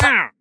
Minecraft Version Minecraft Version 1.21.4 Latest Release | Latest Snapshot 1.21.4 / assets / minecraft / sounds / mob / wandering_trader / hurt3.ogg Compare With Compare With Latest Release | Latest Snapshot
hurt3.ogg